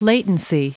Prononciation du mot latency en anglais (fichier audio)
Prononciation du mot : latency